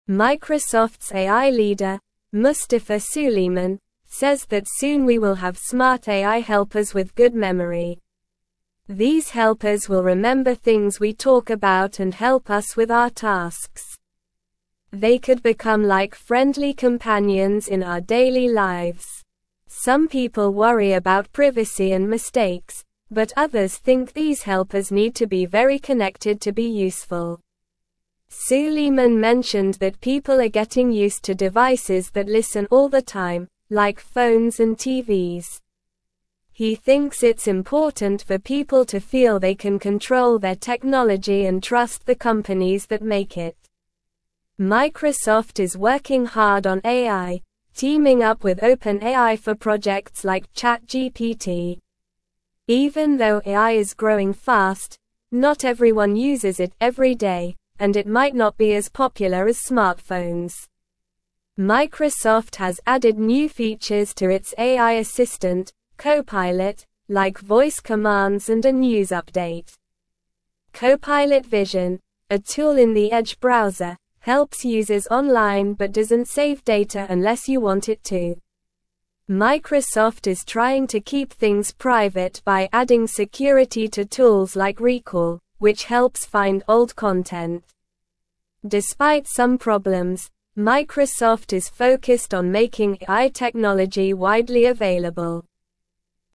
Slow
English-Newsroom-Lower-Intermediate-SLOW-Reading-Microsoft-is-Making-Smart-Friends-to-Help-You.mp3